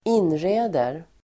Uttal: [²'in:re:der]